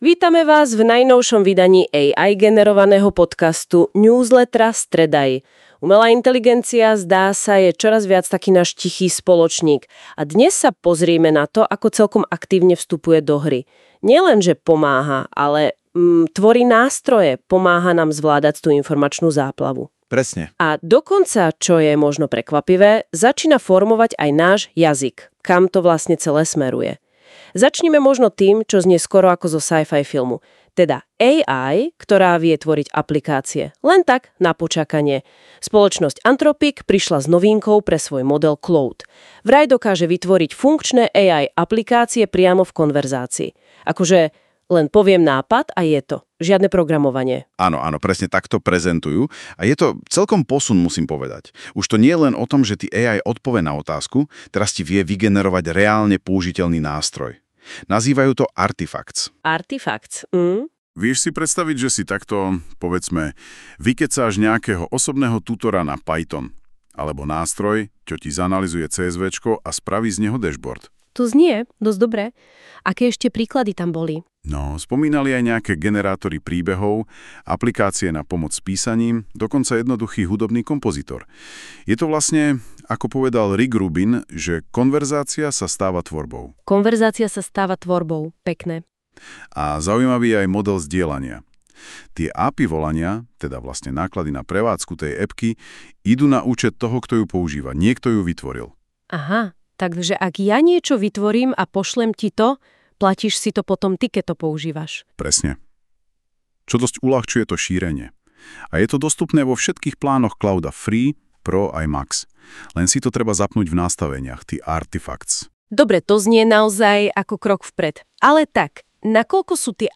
🚀 Prichádzajú najnovšie trendy v AI s týždenným AI generovaným podcast sumárom od STREDAi.